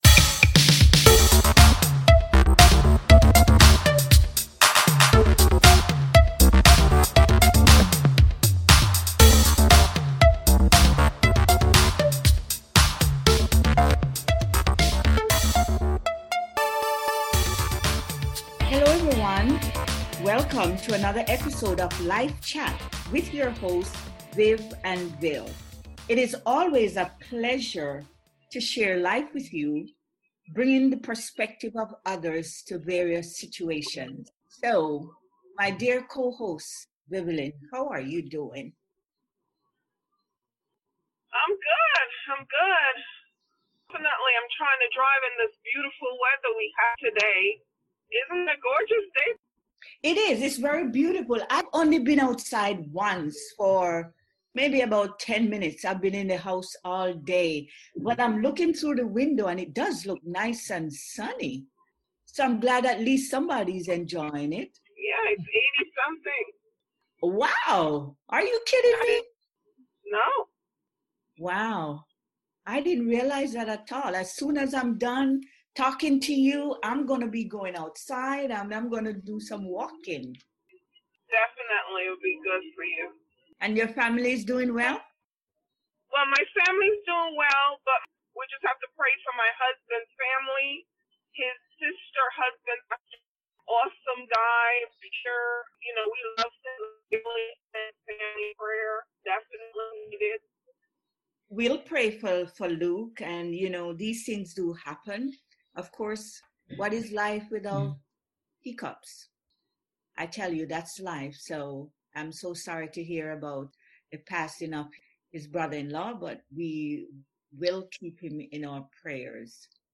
God can turn any impossible situation around…. three ladies share candidly on how God reconciled their marriages.